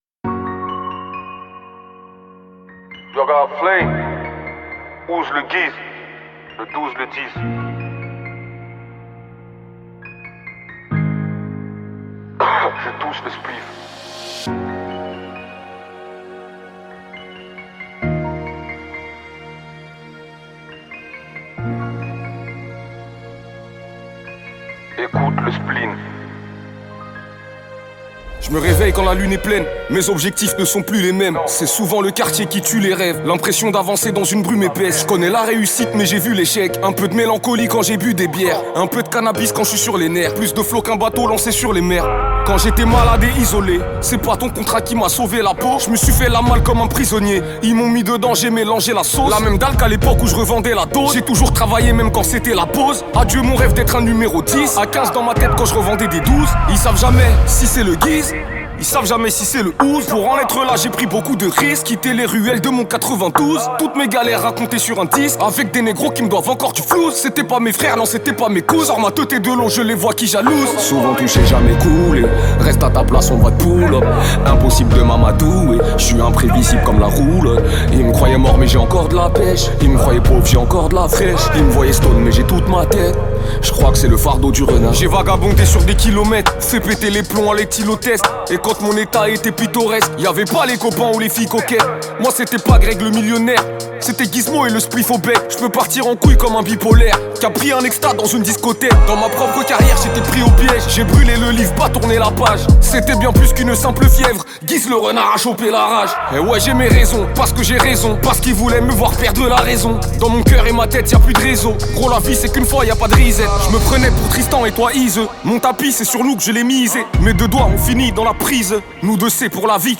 Genres : french rap